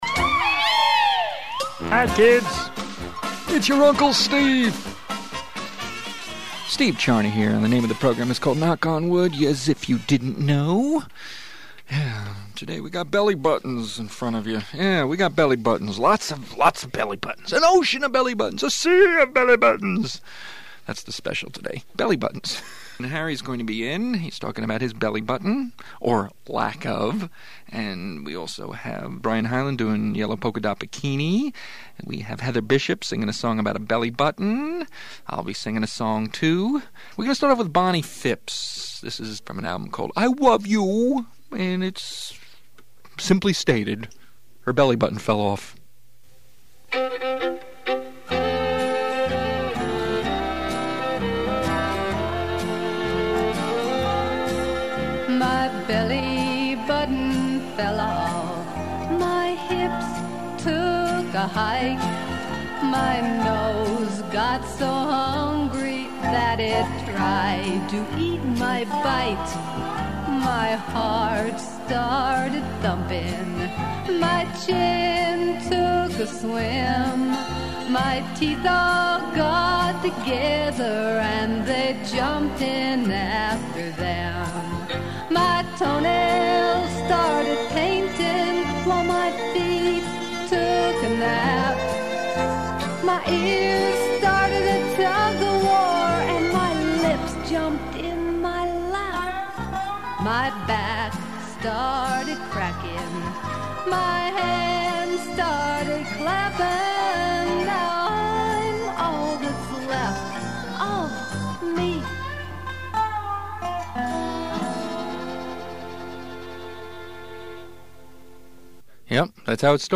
Knock On Wood Comedy Show